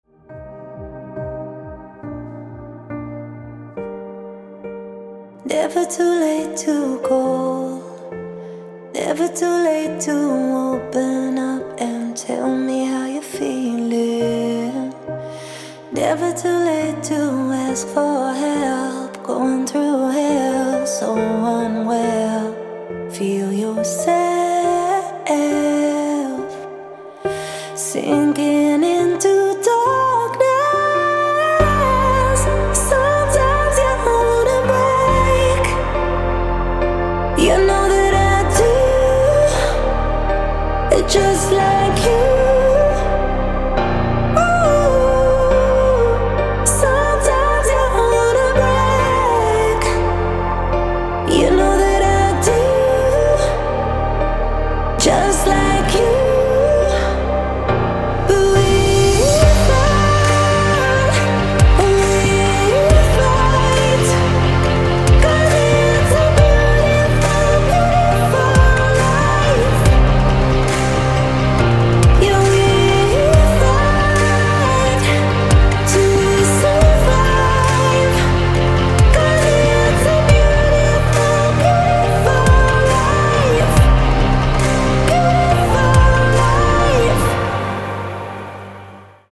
• Качество: 160, Stereo
женский вокал
спокойные
клавишные
пианино
Trance